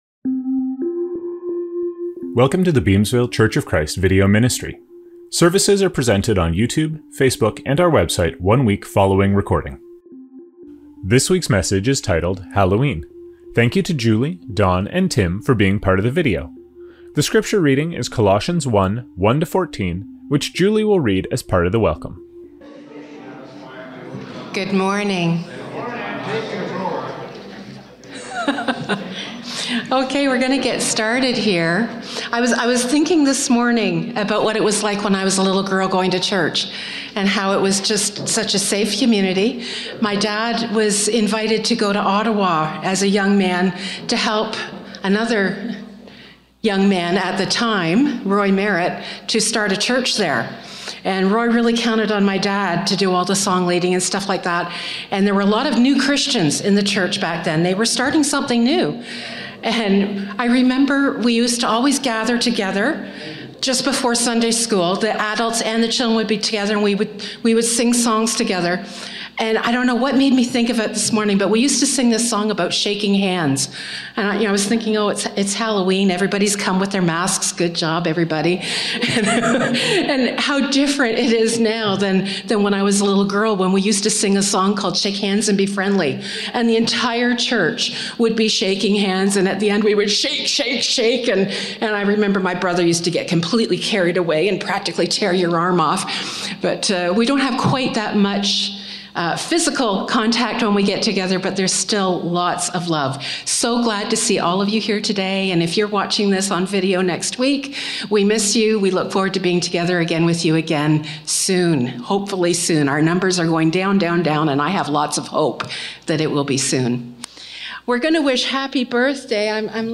Scriptures from this service include: Welcome - Colossians 1:1-14. Sermon